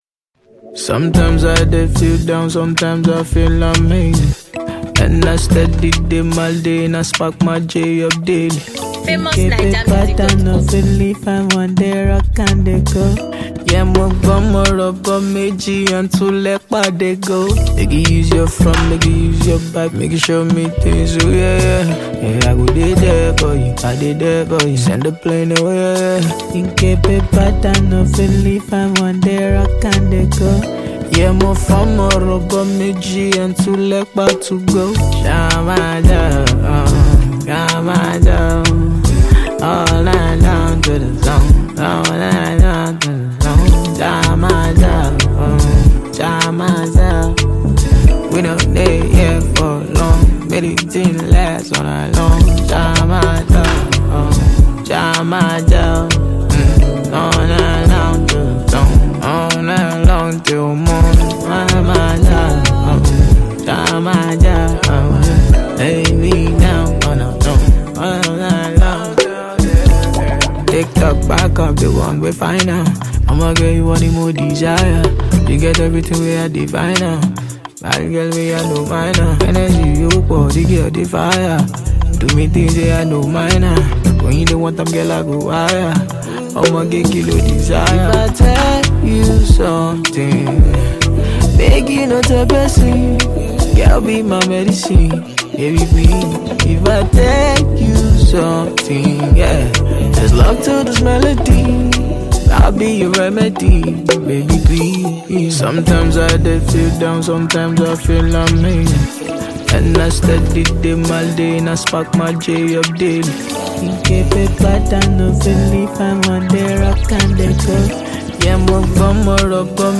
an eminent vocalist musician from Nigeria